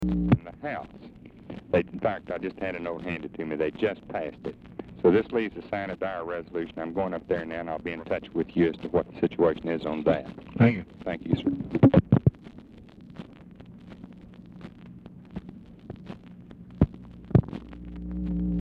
Telephone conversation
Format Dictation belt
Location Of Speaker 1 Oval Office or unknown location